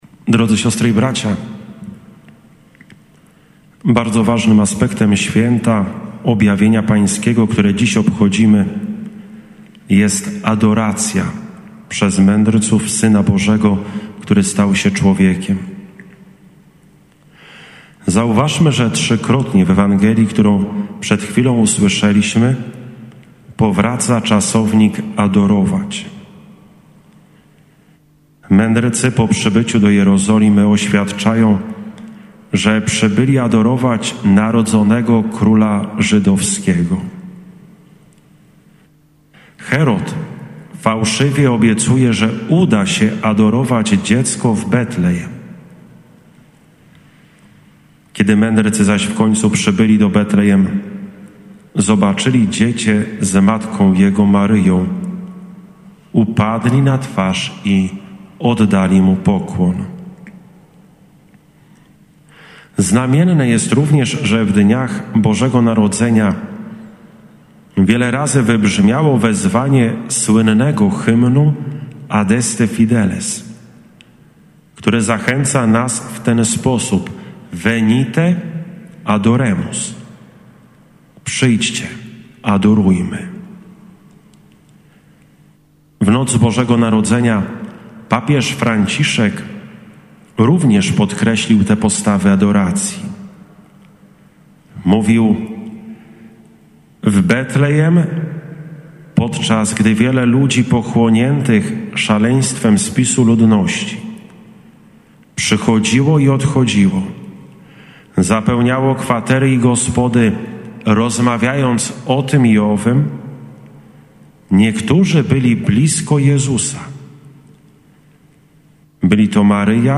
W uroczystość Epifanii Mszy św. konwentualnej przewodniczył Ks. Abp Antonio Guido Filipazzi, Nuncjusz Apostolski w Polsce.
6-01-24-homilia-nuncjusz.mp3